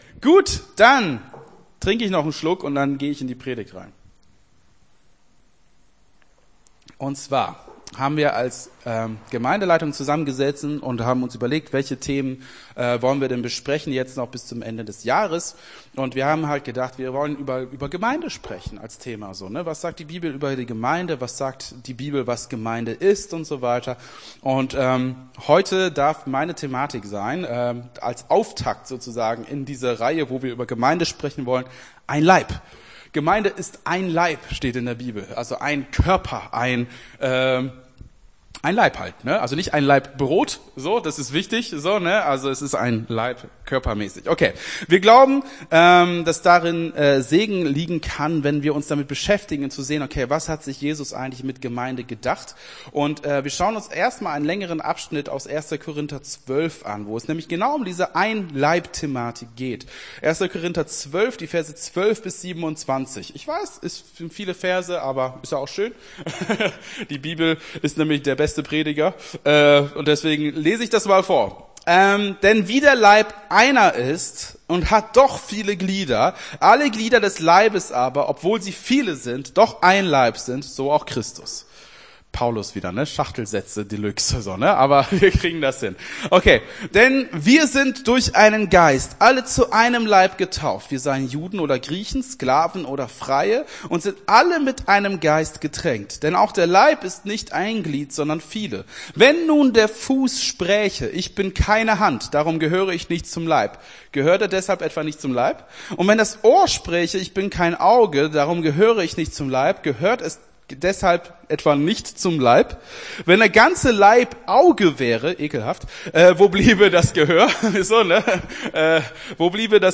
Gottesdienst 05.11.23 - FCG Hagen